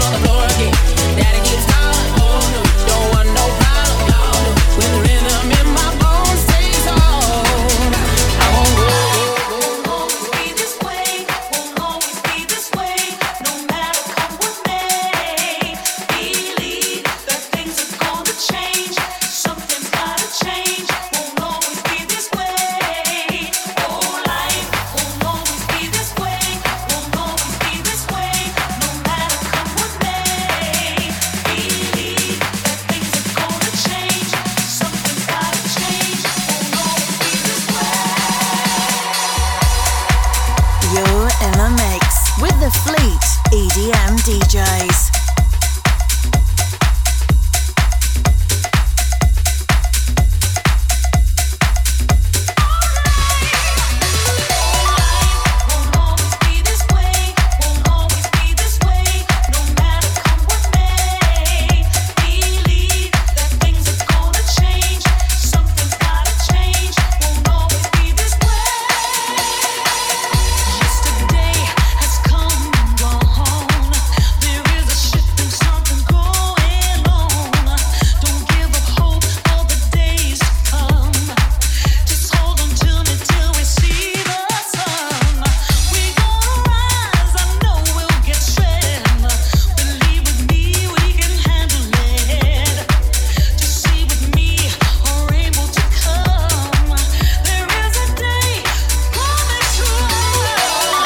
Genre: House, Electronic, Dance.